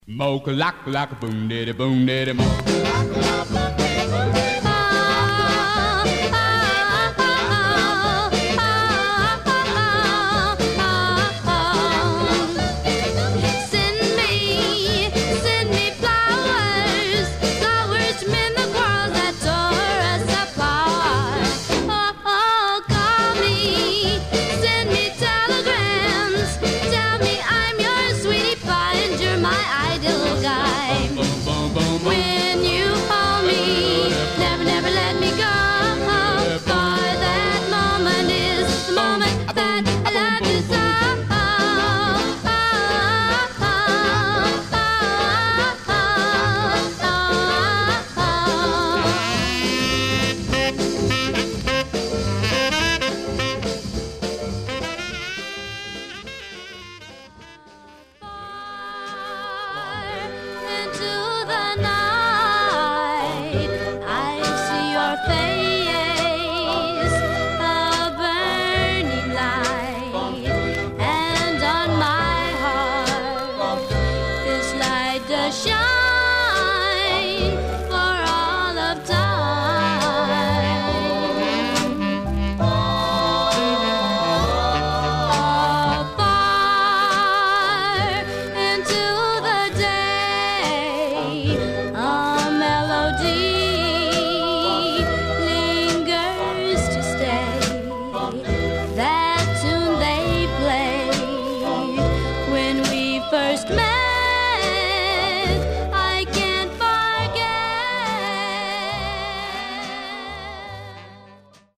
Mono
Black Female Group Condition